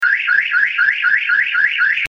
Car Alarm
Car_alarm.mp3